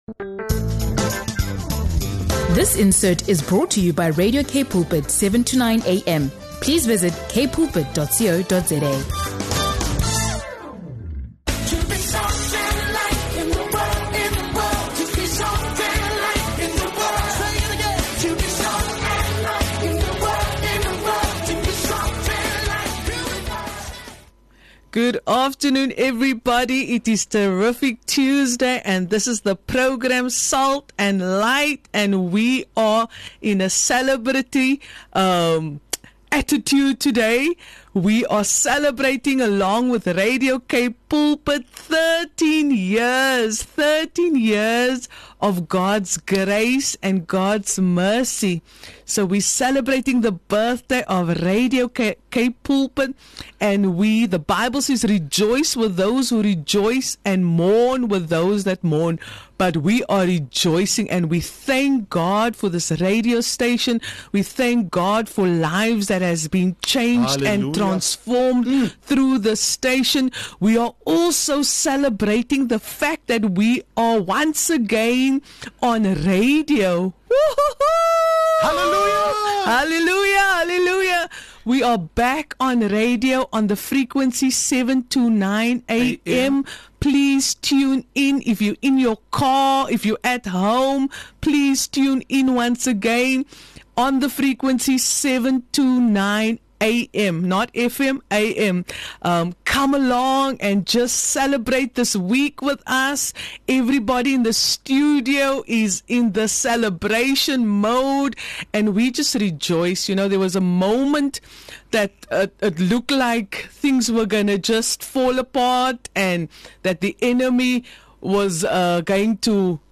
Get ready for encouragement, worship moments, and an anointed message that will lift your spirit and strengthen your faith.